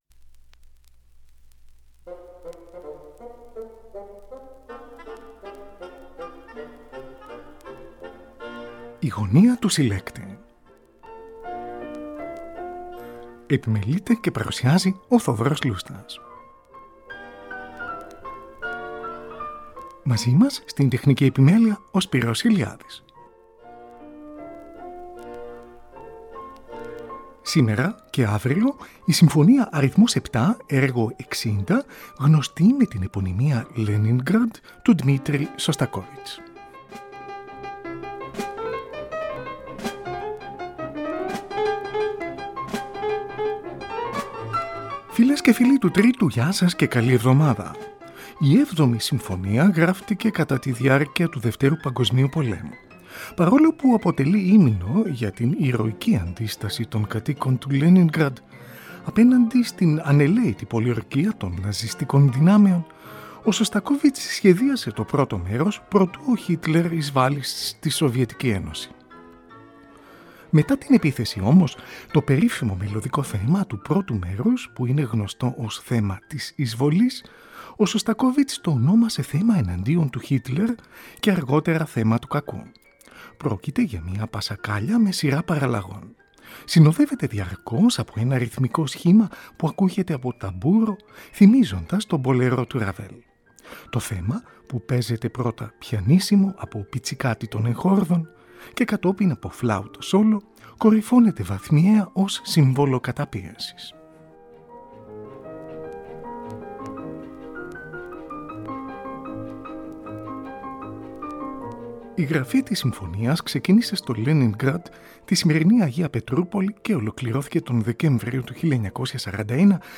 συμφωνία αρ.7